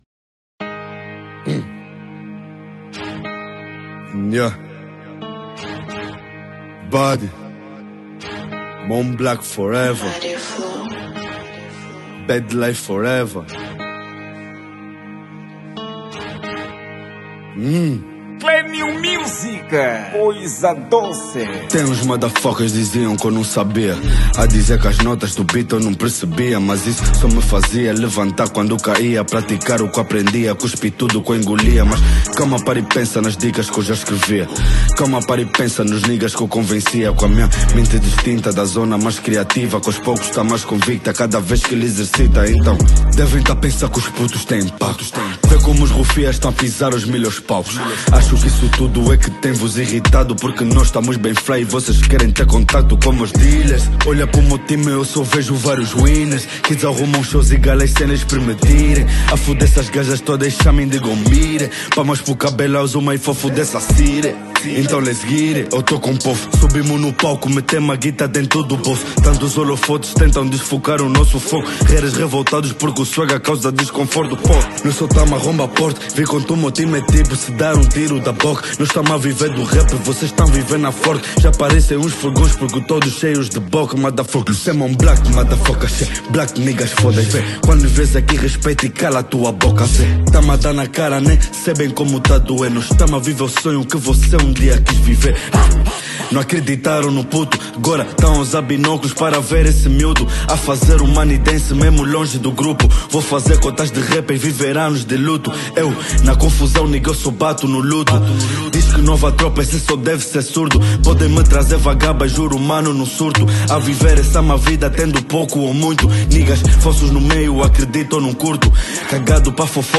Género: Trap Tamanho